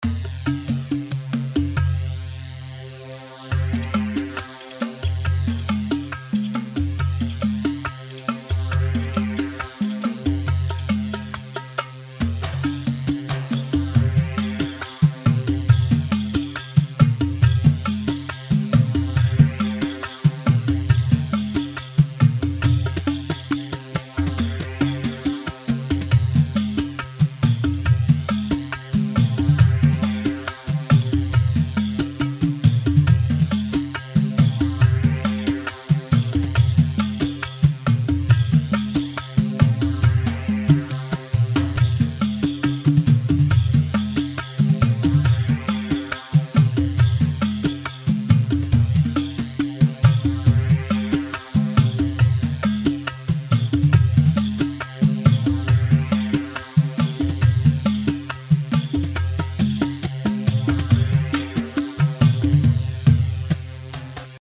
Rhumba A 4/4 138 رومبا
Rhumba-A-138-mix.mp3